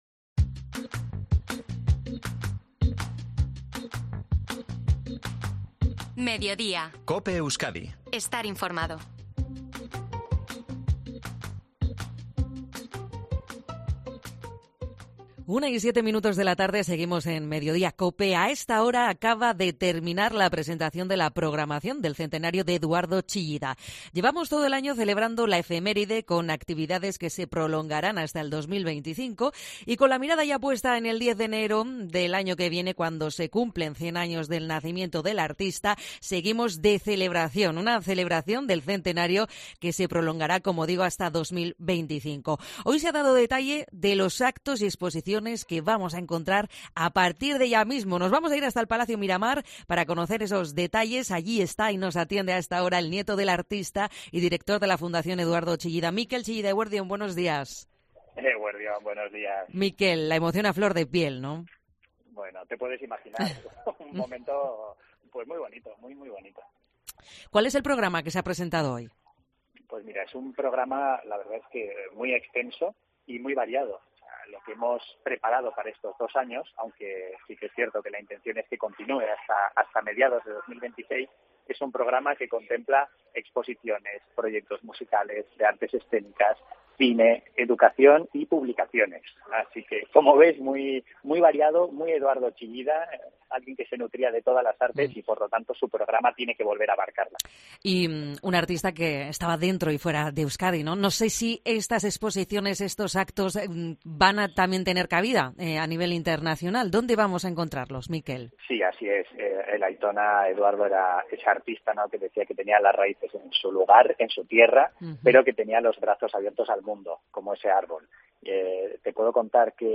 Entrevistado en MEDIODIA COPE EUSKADI